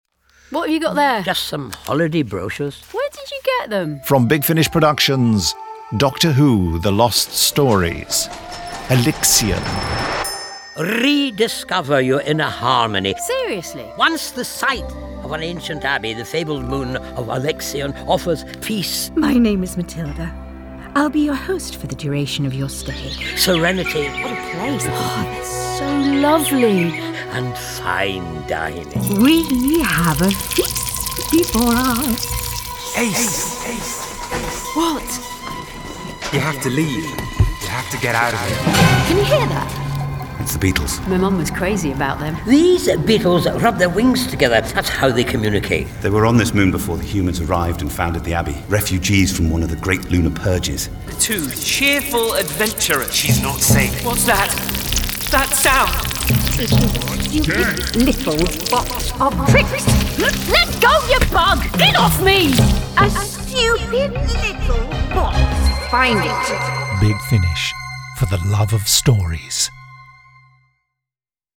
Award-winning, full-cast original audio dramas from the worlds of Doctor Who, Torchwood, Blake's 7, Class, Dark Shadows, Avengers, Omega Factor, Star Cops, Sherlock Holmes, Dorian Gray, Pathfinder Legends, Prisoner, Adam Adamant Lives, Space 1999, Timeslip, Terrahawks, Space Precinct, Thunderbirds, Stingray, Robin Hood, Dark Season, UFO, Stargate, V UK, Time Tunnel, Sky, Zygon Century, Planet Krynoid
Starring Sylvester McCoy Sophie Aldred